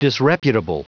Prononciation du mot : disreputable
disreputable.wav